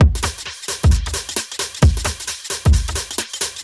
Index of /musicradar/uk-garage-samples/132bpm Lines n Loops/Beats